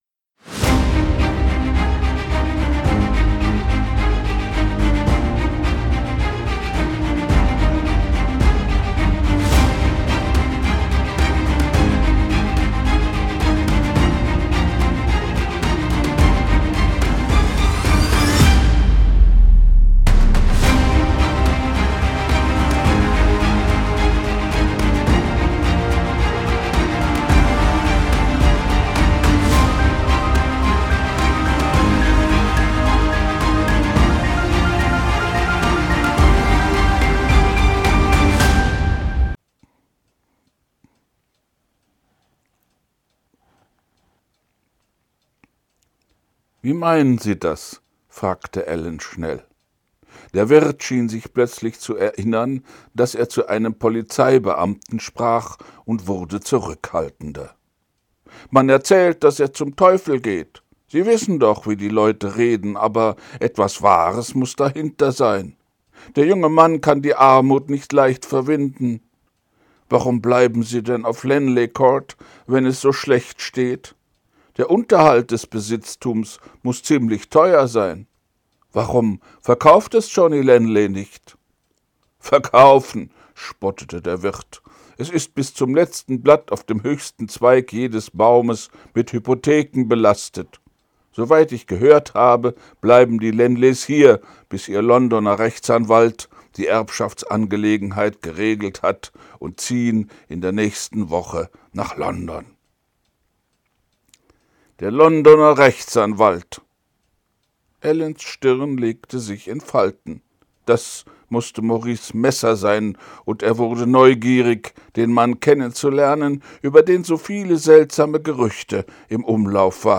ich lese vor wallace hexer 2